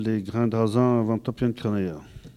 Sallertaine
collecte de locutions vernaculaires